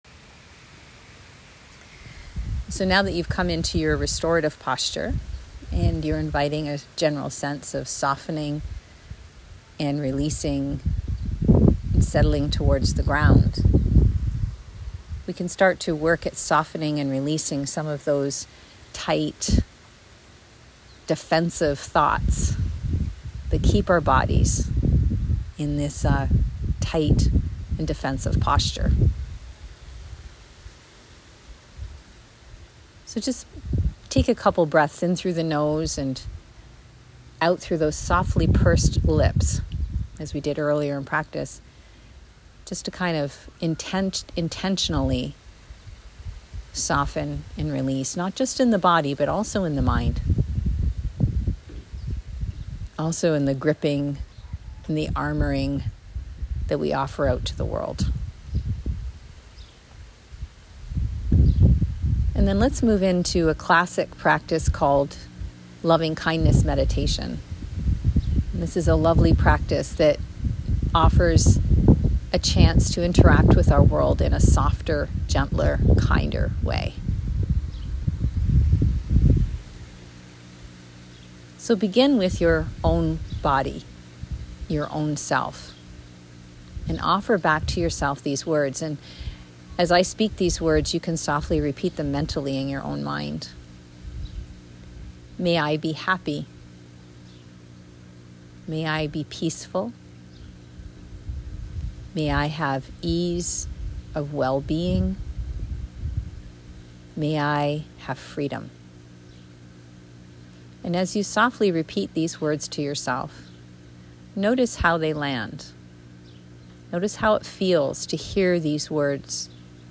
Loving-Kindness-Meditation.mp3